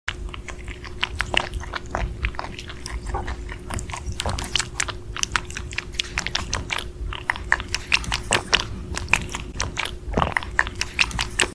Super cute sound effects free download